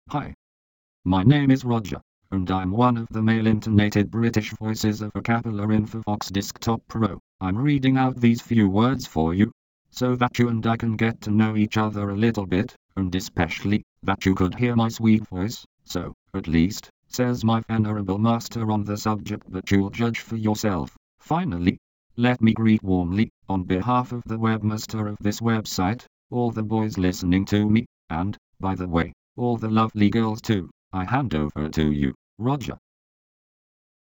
Texte de démonstration lu par Roger, voix masculine anglaise d'Acapela Infovox Desktop Pro
Écouter la démonstration de Roger, voix masculine anglaise d'Acapela Infovox Desktop Pro